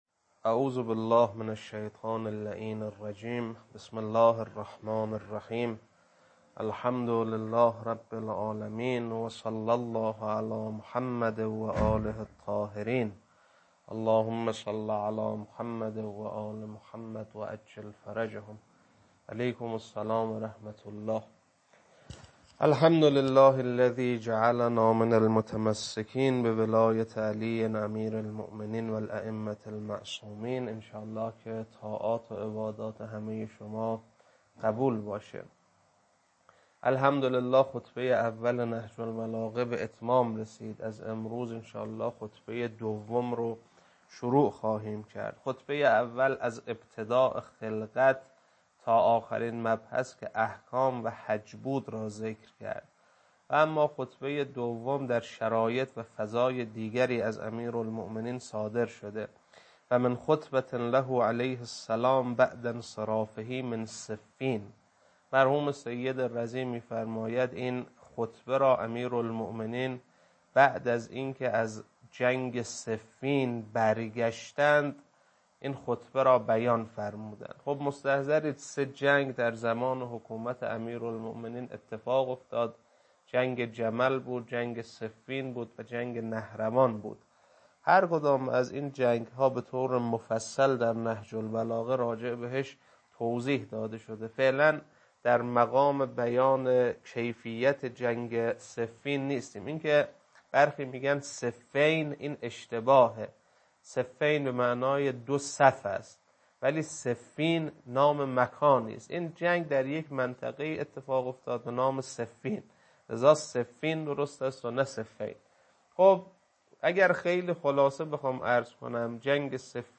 خطبه-2-بخش-اول.mp3